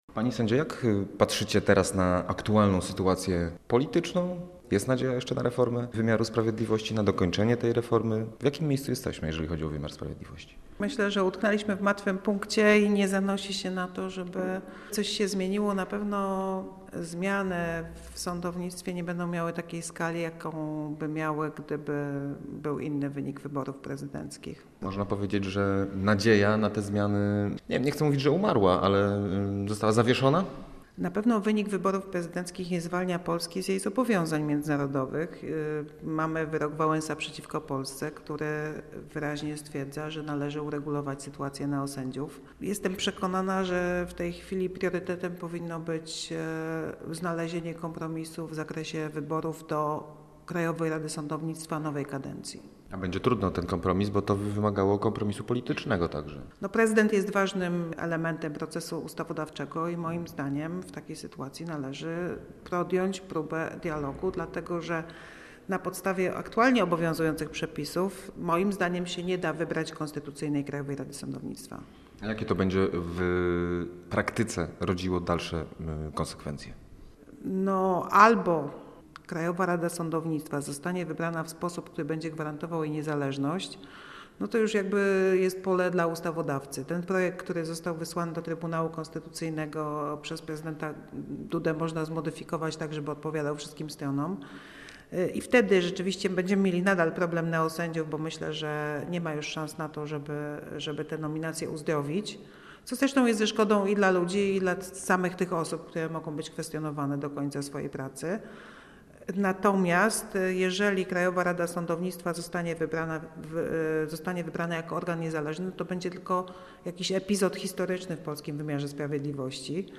Utknęliśmy w martwym punkcie i nie zanosi się na to, by coś się zmieniło – powiedziała w rozmowie z Radiem Gdańsk sędzia Dorota Zabłudowska z gdańskiego oddziału Stowarzyszenia Sędziów Polskich „Iustitia”.